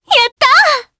Selene-Vox_Happy4_jp.wav